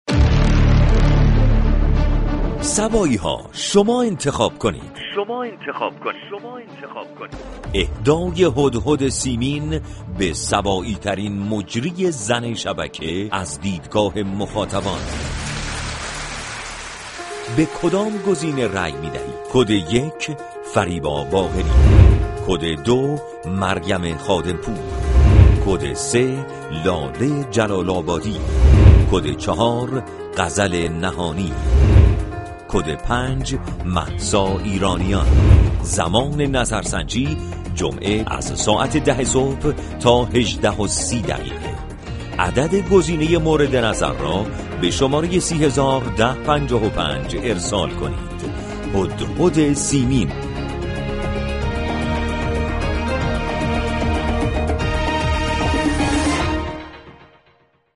به گزارش روابط عمومی رادیو صبا، «شما و صبا» یك مجله رادیویی با فضای شاد است كه بر اساس تعامل با مخاطبان طراحی شده است این برنامه روزهای جمعه از این شبكه تقدیم مخاطبان می شود.